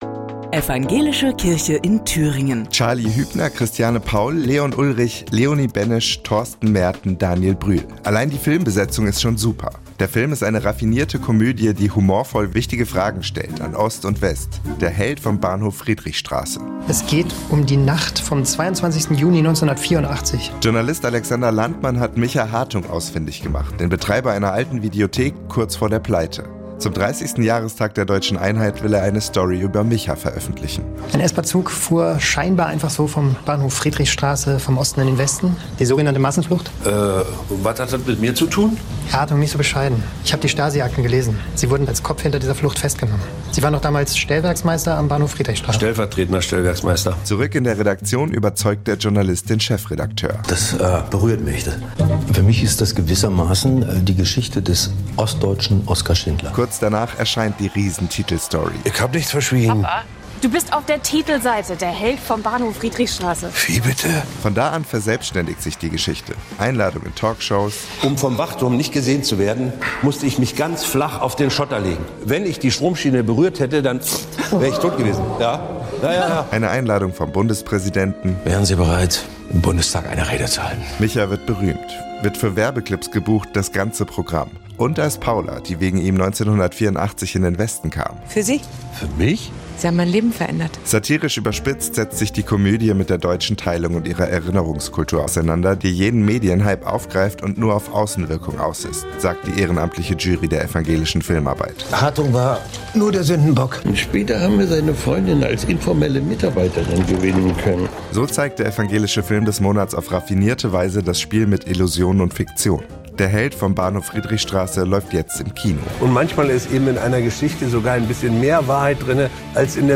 Die Thomaskirche direkt daneben wird dann zur Lichterkirche mit Programm und Konzerten. Zwei Frauen wollen den Winzerglühwein testen.